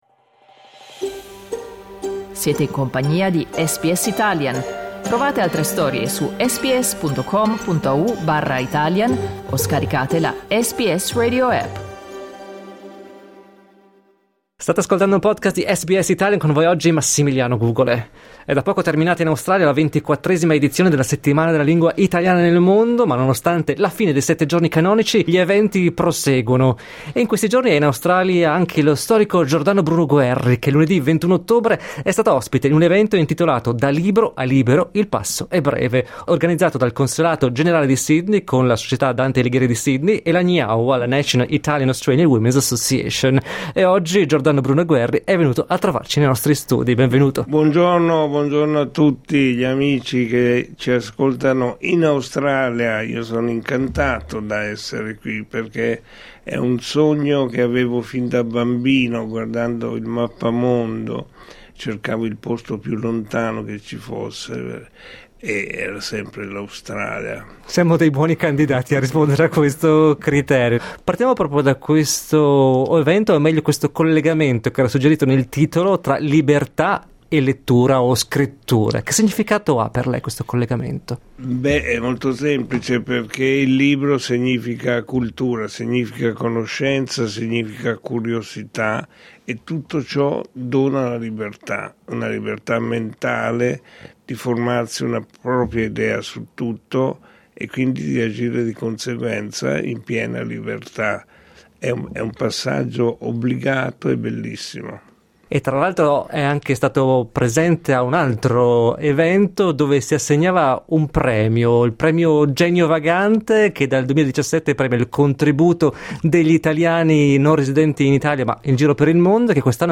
Lo storico e giornalista Giordano Bruno Guerri ha visitato l'Australia nel corso della Settimana della Lingua Italiana nel Mondo. Ospite dei nostri studi, ha conversato con noi di lingua, espatrio, storia e molto altro.